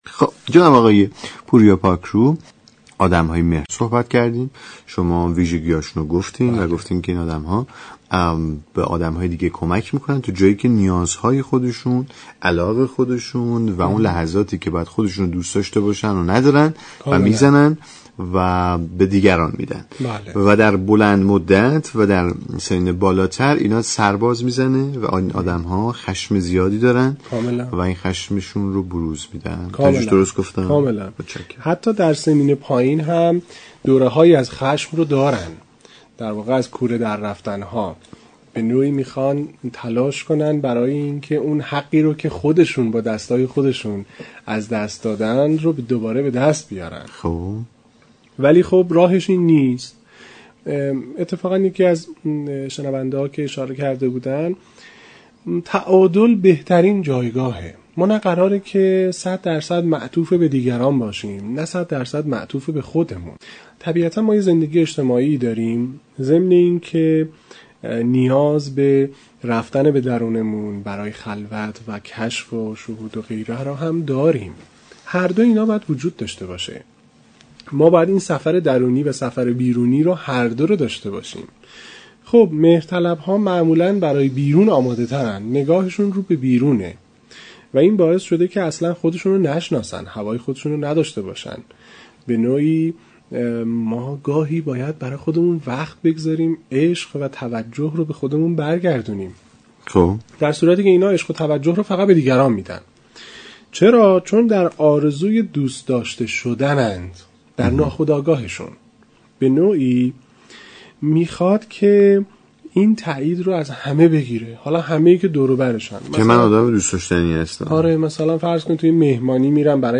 برنامه مشاوره گفتگو محور